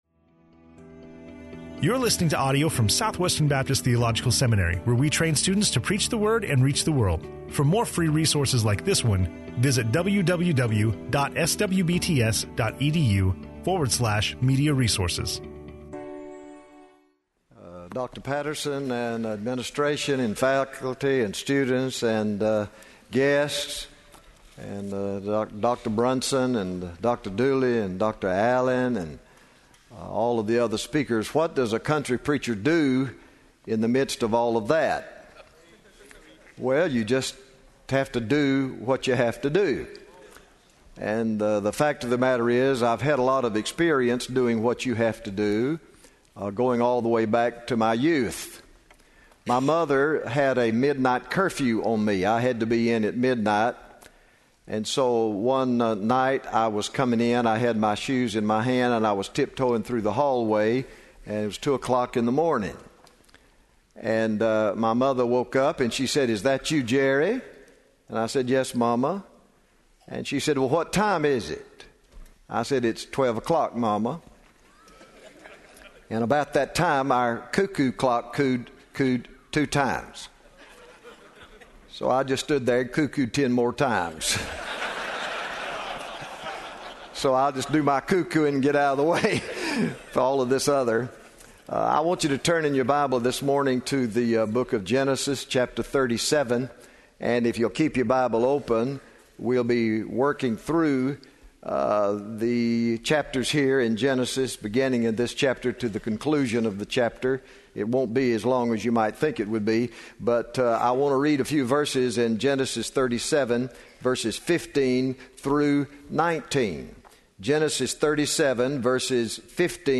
speaking on Genesis 37:15-19 in SWBTS Chapel on Tuesday March 5, 2013